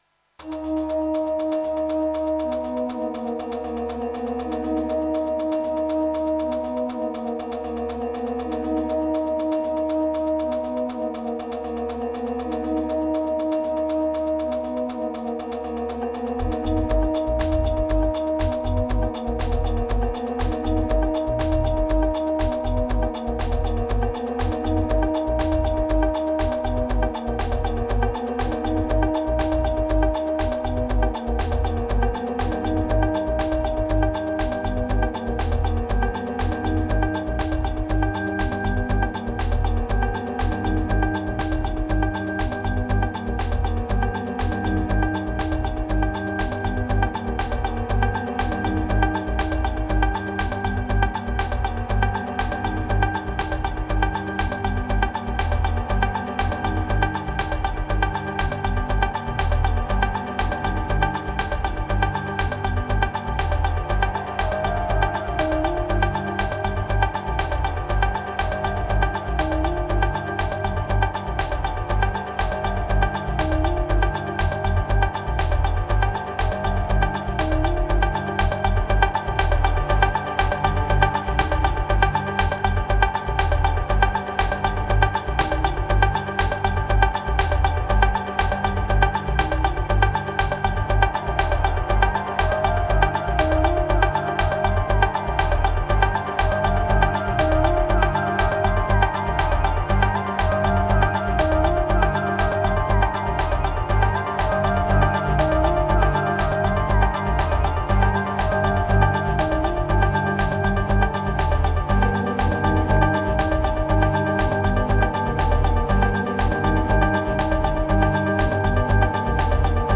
Electro Synthesizer Analog Ambient Moods Cognitive Ladder